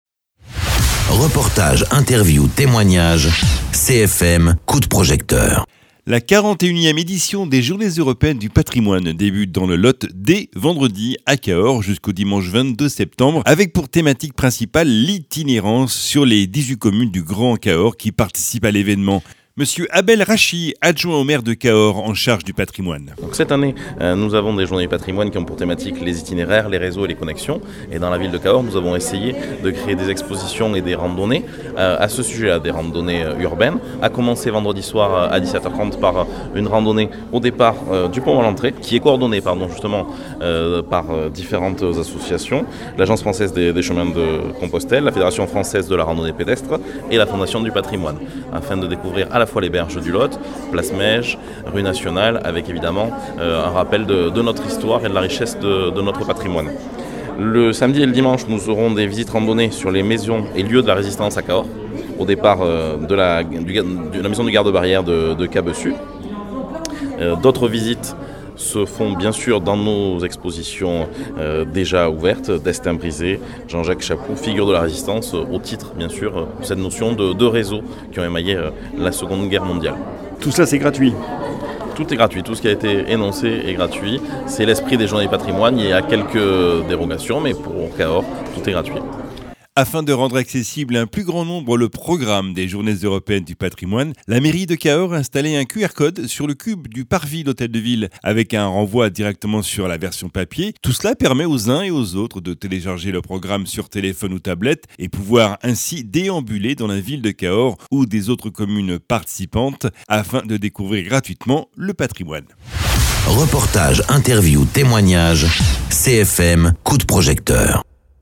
Interviews
Invité(s) : Mr Abel RASCHI, adjoint au maire en charge du patrimoine